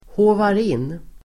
Ladda ner uttalet
Uttal: [²h'å:var]